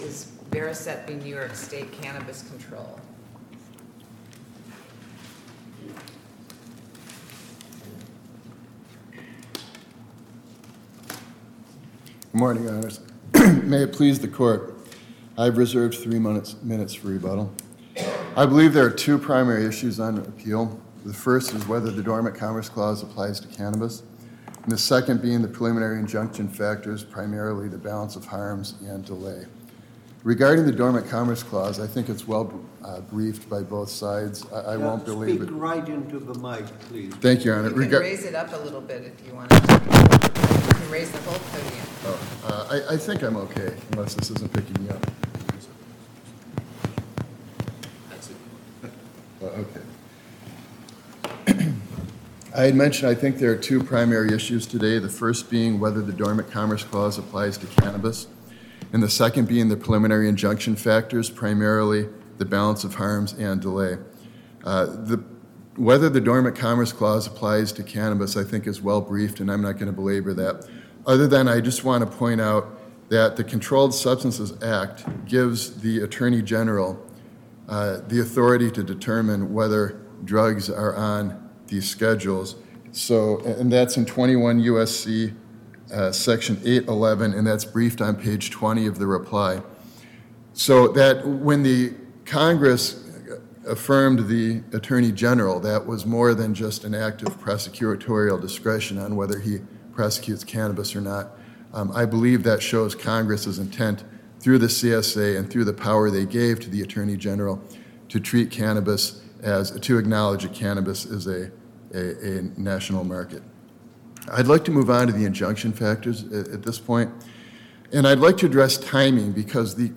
Explore the key arguments and judicial perspectives from the Second Circuit's oral arguments in Variscite NY Four, LLC v. New York State Cannabis Control Board. This pivotal case examines whether the dormant Commerce Clause applies to state-licensed cannabis programs and the balance between interstate commerce and restorative justice efforts.